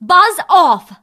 bea_hurt_vo_01.ogg